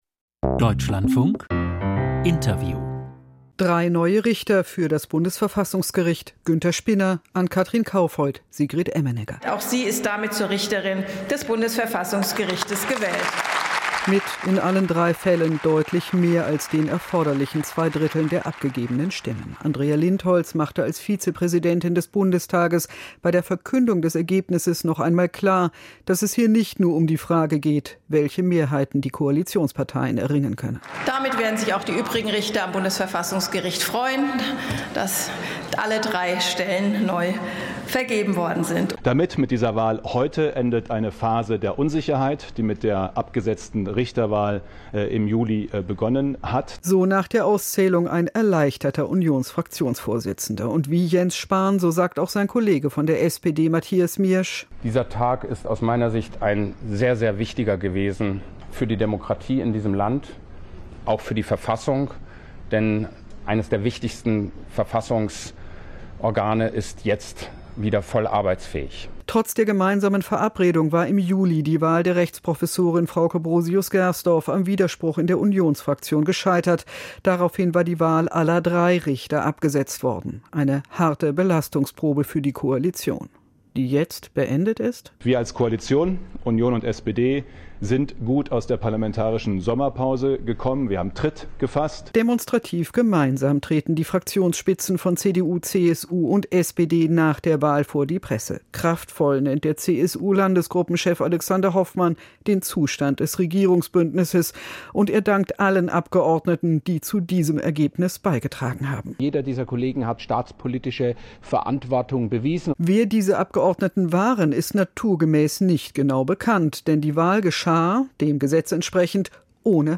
BVerfG-Kandidaten: Interview